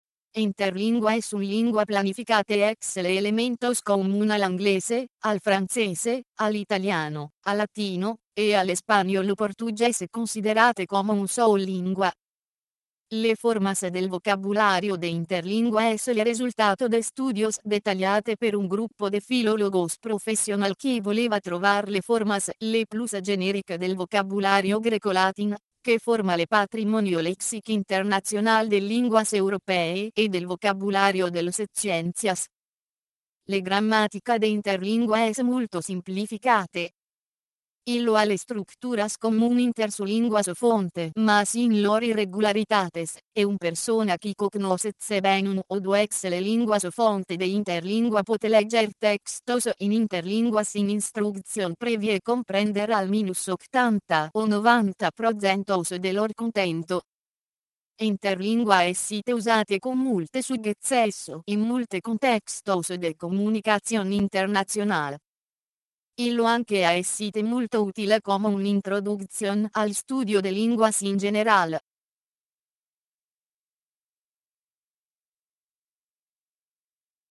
Der nachfolgende Interlinguatext wird durch den Computer gelesen: